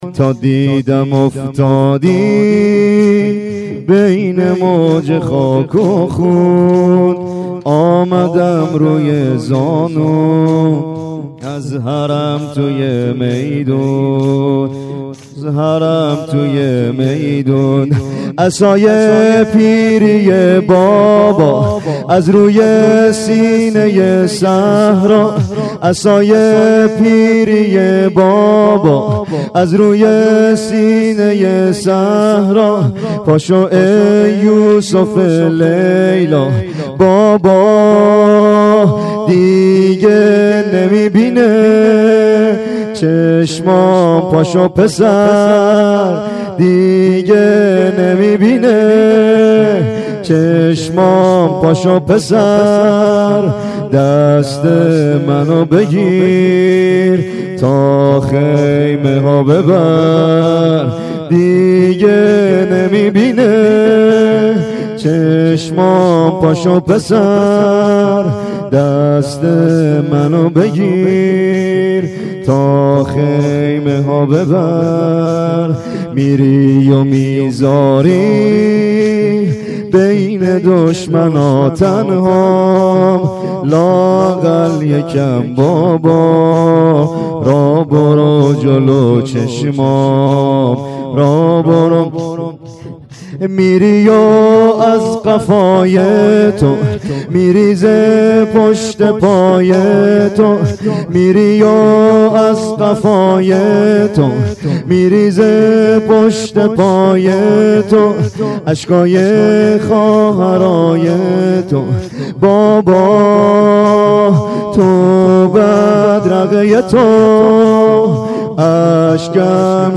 گلچین زمینه های محرم 93
زمینه شب هشتم : تا دیدم افتادی بین موج خاک و خون